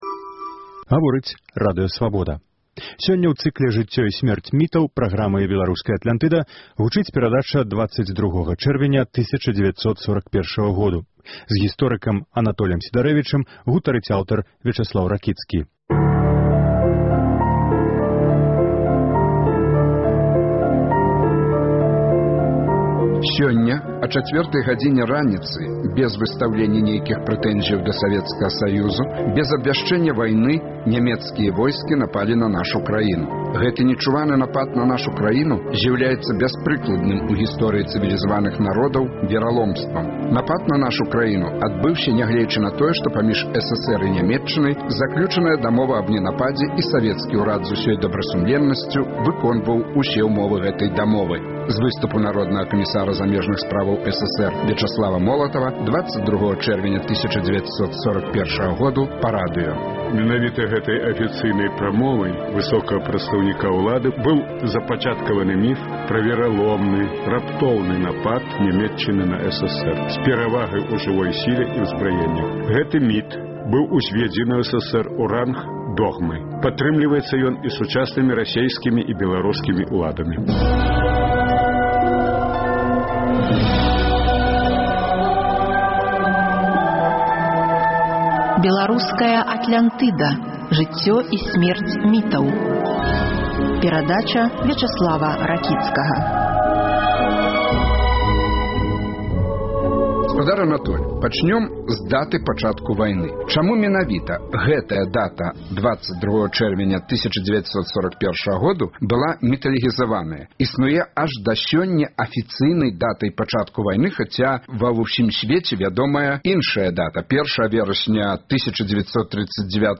Normal 0 Normal 0 Сёньня ў цыкле "Жыцьцё і сьмерць мітаў" – перадача "22 чэрвеня 1941 году". Зь гісторыкам